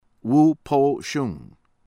WANG JIN-PING WAHNG   JEEN   PEENG